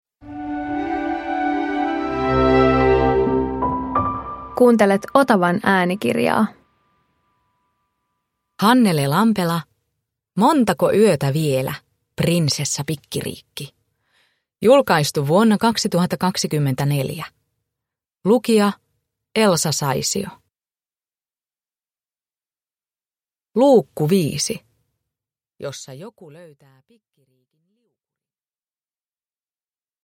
Montako yötä vielä, Prinsessa Pikkiriikki 5 – Ljudbok